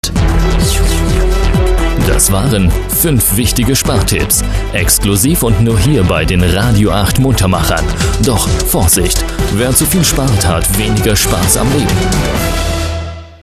Sprecher deutsch für Radiowerbung, Tv-Werbung, Pc-Spiele, Industriefilme...
Sprechprobe: Industrie (Muttersprache):
german voice over talent